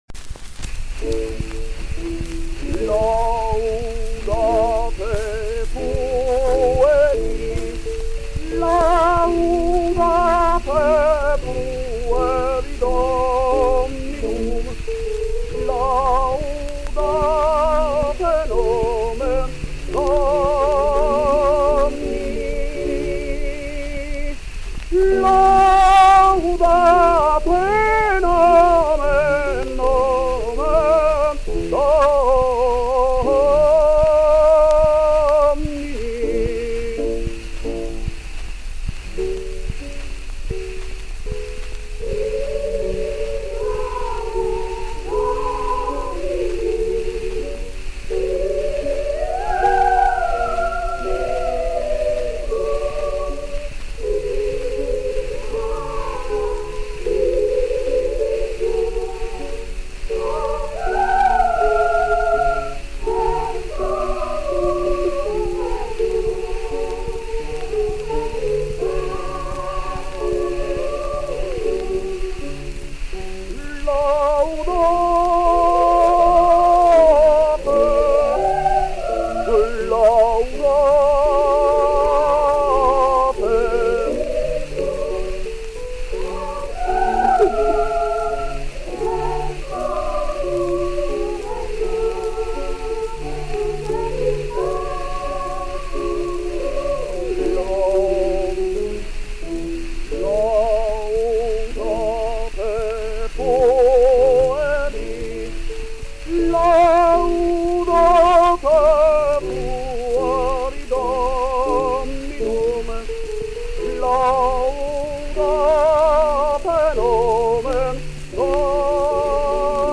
Recorded in April 1902.